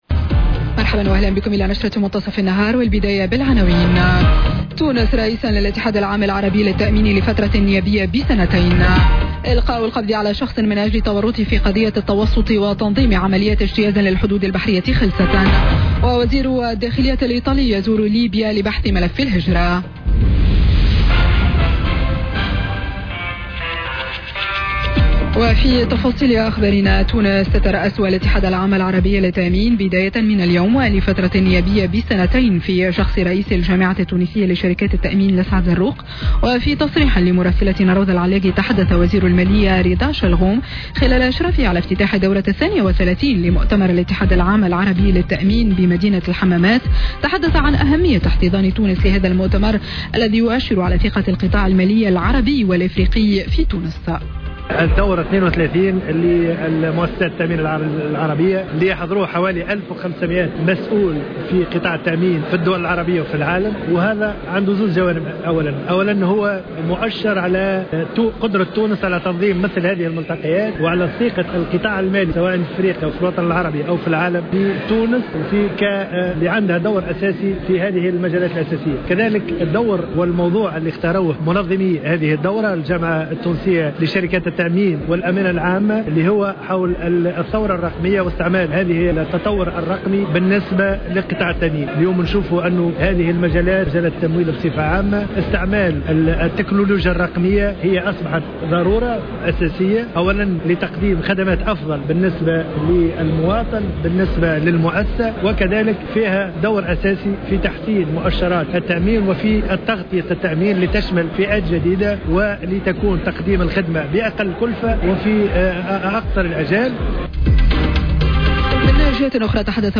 Journal Info 12h00 du lundi 25 Juin 2018